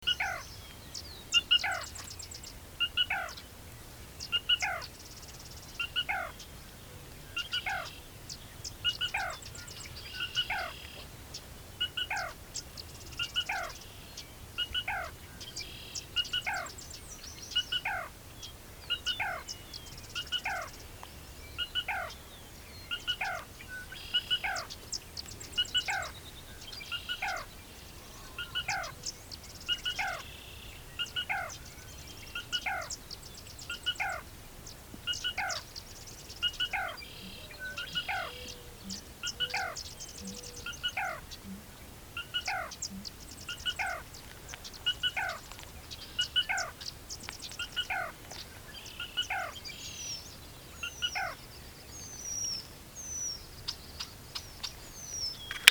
As you might guess, it is extremely difficult to see, but at least it has a very distinctive primary vocalization, usually rendered “kee-kee-doo”.
To our surprise, an hour before sunset, one started calling sporadically, and we eventually were able to get within 15 feet and listen to it call almost continuously for about 20 minutes!
Recording we made of Black Rail (with Sedge Wren, Red-winged Blackbird, and Mourning Dove in the background), Quivira NWR, KS:
black_rail_2__quivera_nwr_ks.mp3